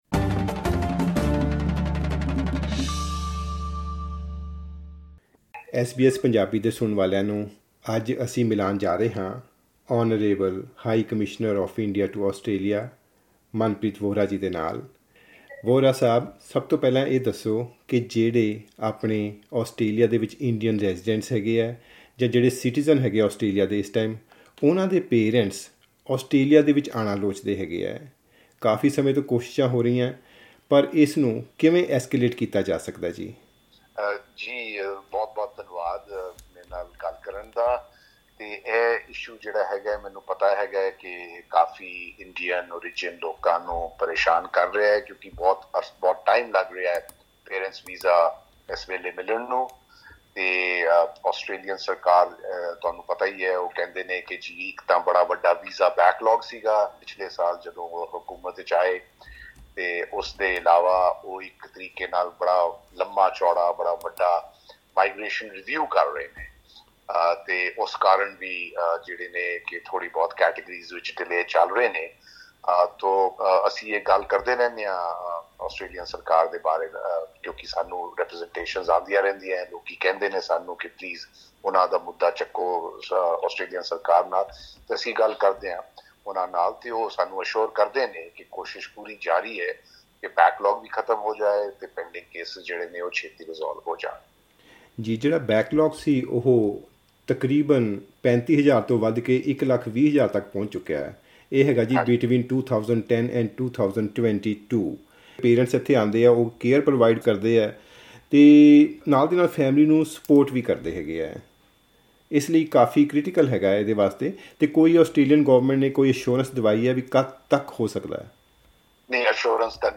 India's High Commissioner to Australia, Manpreet Vohra, discusses parent visa processing delays, the ban on Indian student admissions by some Australian universities and community harmony ahead of the two-day G20 summit in India starting on 9 September.
In an exclusive interview with SBS Punjabi, India's High Commissioner to Australia, Manpreet Vohra, addressed a range of community issues including visa processing delays, most notably the parent visa program - a major concern for many Indian migrants.